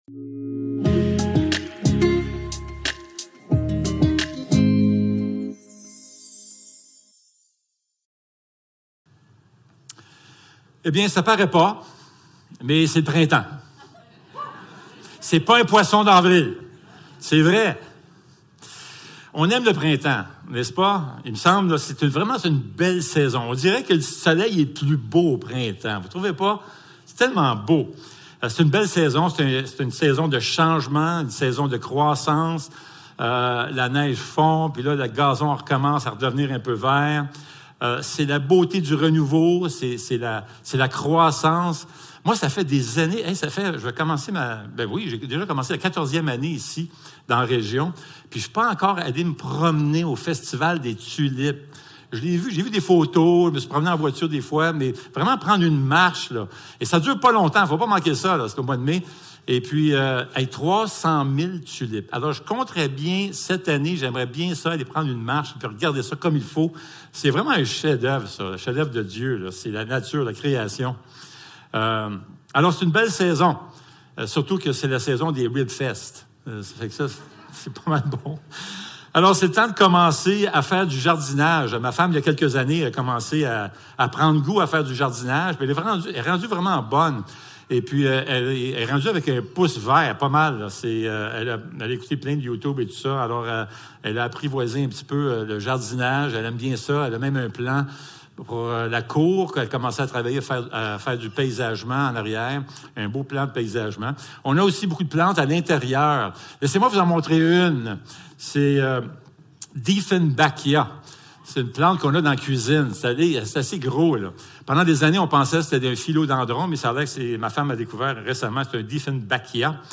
Jacques 1.19-27 Service Type: Célébration dimanche matin Vers la maturité spirituelle #3 La création nous rappelle que Dieu est le chef jardinier.